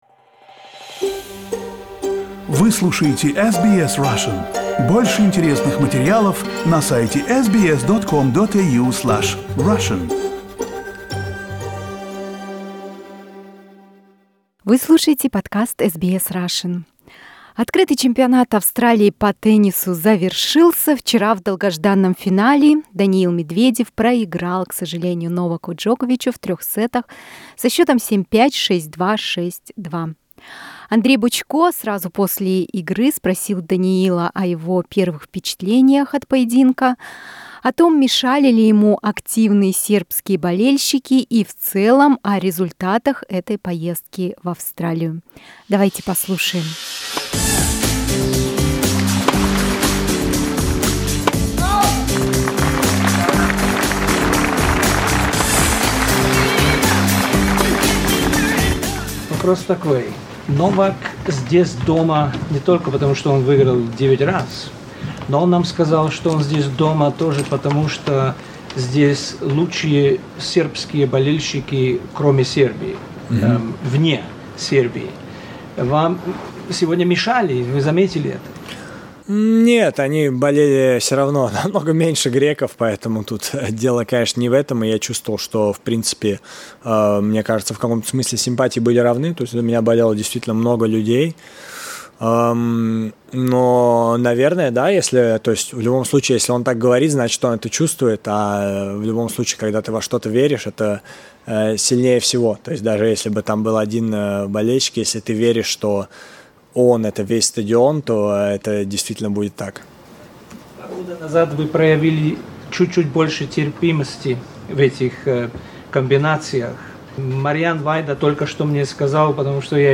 Interview with a Russian tennis player Daniil Medvedev after the Australian Open Final.